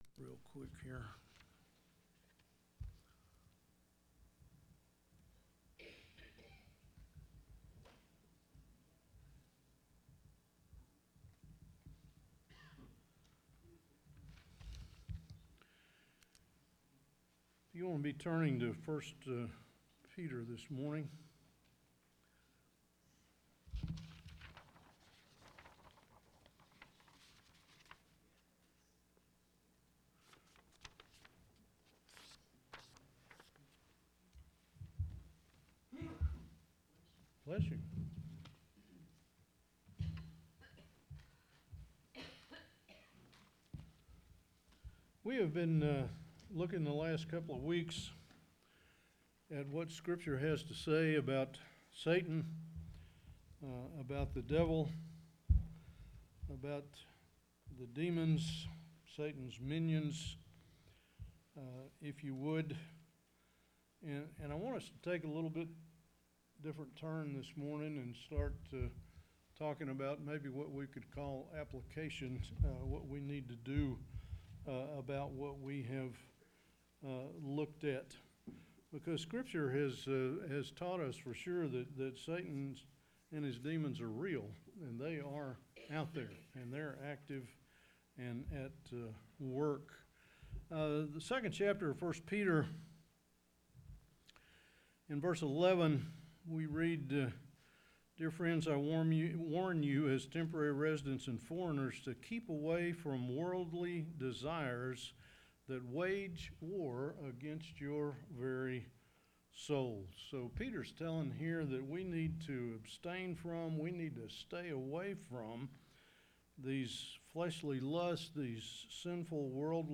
Sunday Bible Class